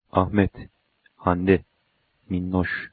The names of people (and pets) often receive a last syllable accent.